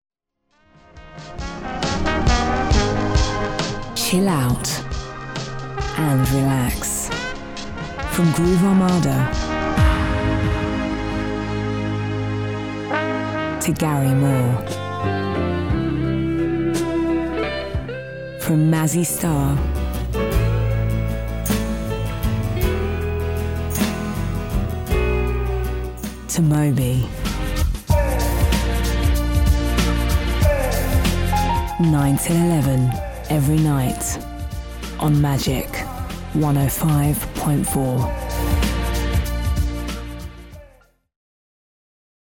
Neutral London.
• Female
• London
Magic FM trailer